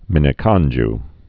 (mĭnĭ-kŏnj)